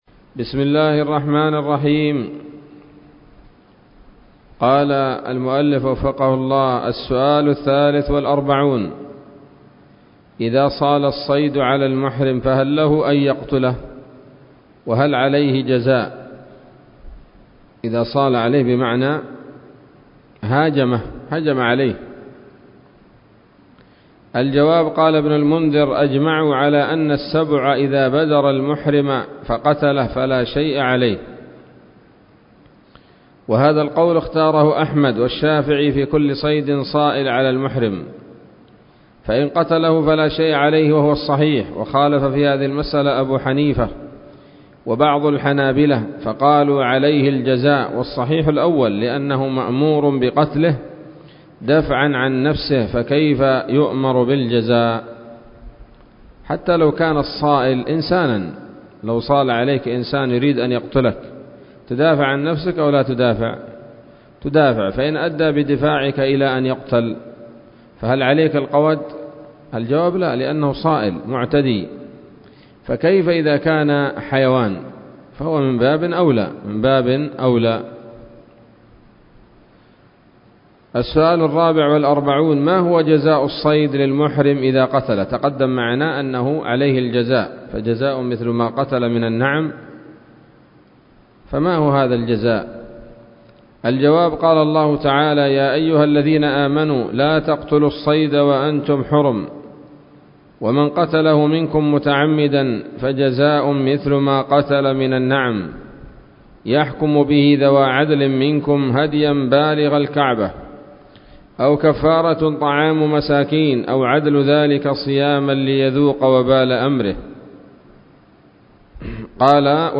الدرس السابع والعشرون من شرح القول الأنيق في حج بيت الله العتيق